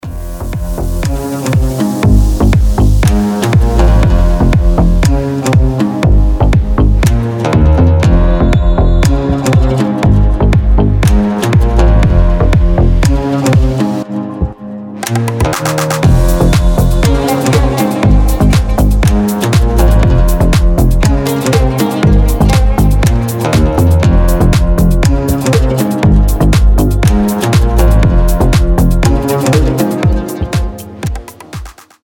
• Качество: 320, Stereo
deep house
без слов
басы
восточные
турецкие